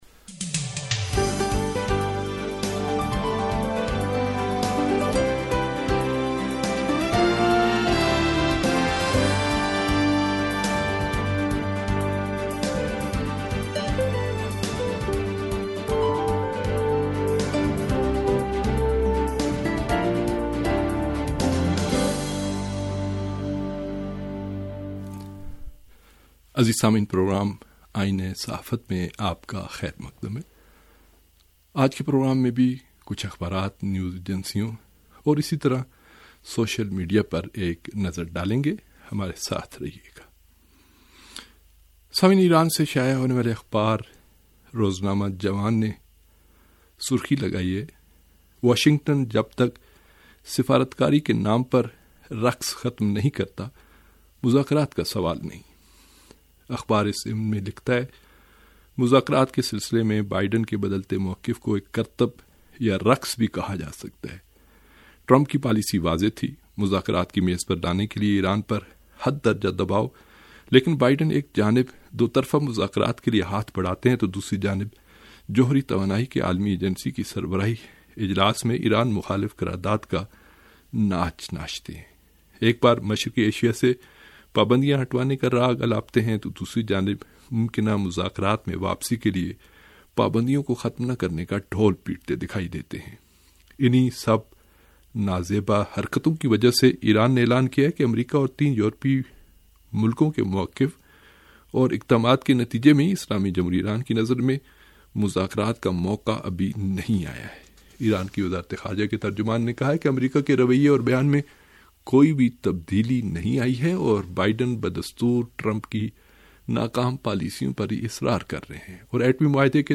ریڈیو تہران کا اخبارات کے جائزے پرمبنی پروگرام - آئینہ صحافت